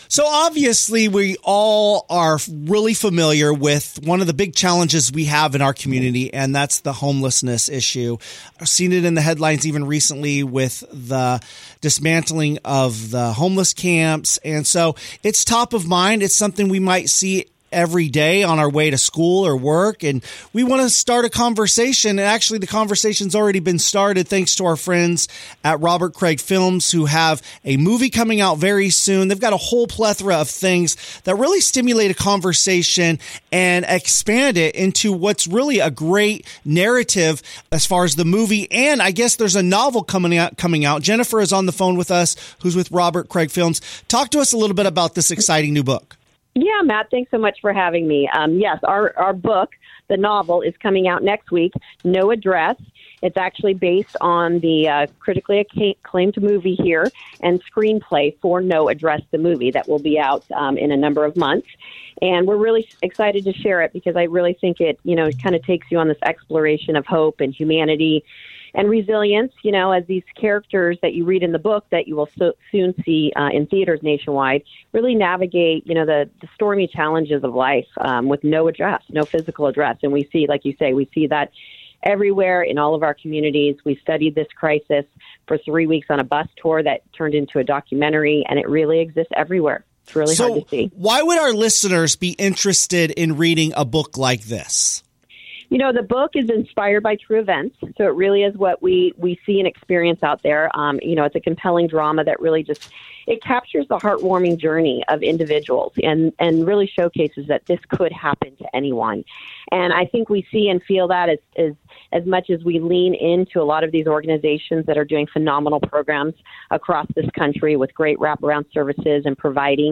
Novel Interview